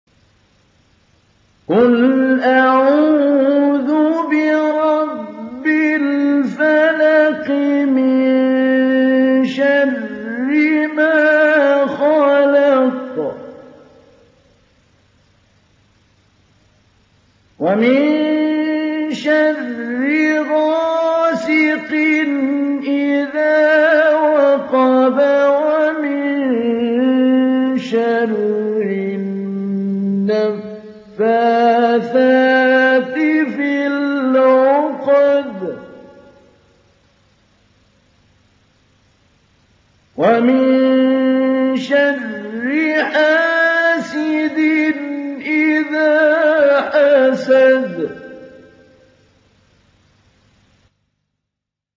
Télécharger Sourate Al Falaq Mahmoud Ali Albanna Mujawwad